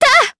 Talisha-Vox_Attack3_Jp.wav